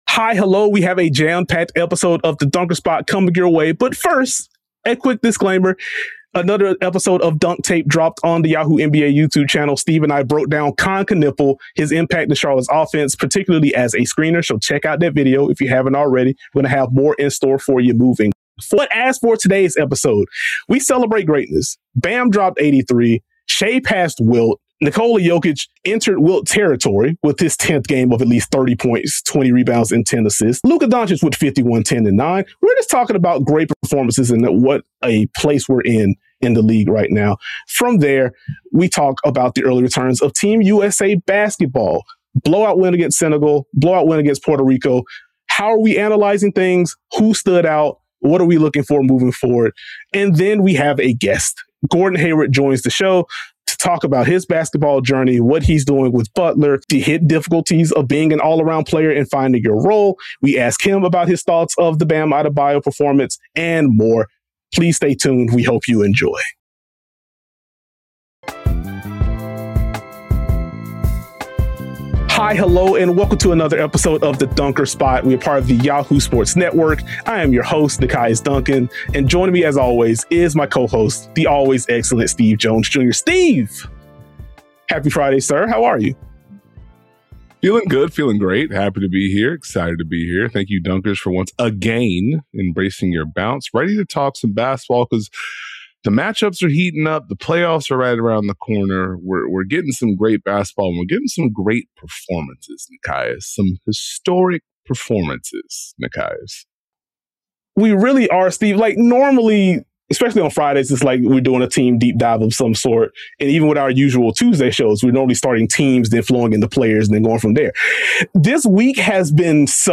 Finally, Gordon Hayward joins the show!
(51:17) Gordon Hayward interview